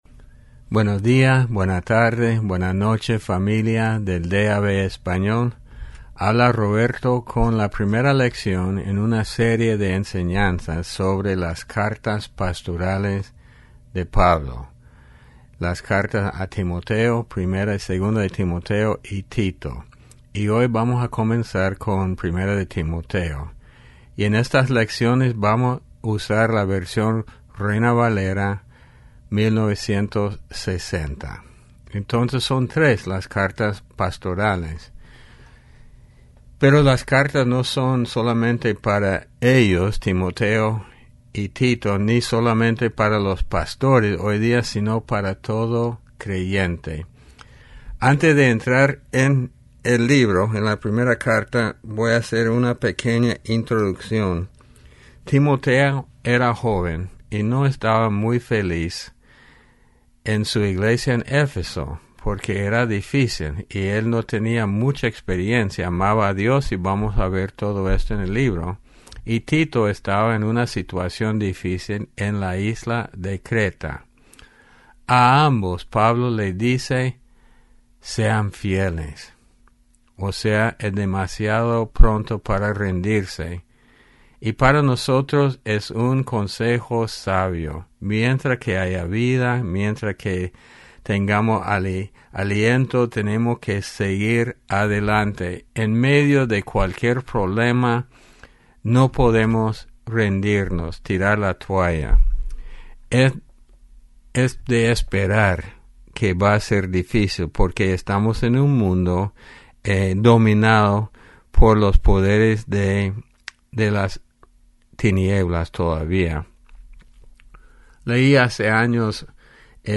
Lección 01 Las Cartas Pastorales (Timoteo y Tito)